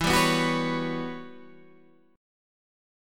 E Minor 6th Add 9th